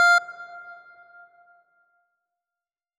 F2.wav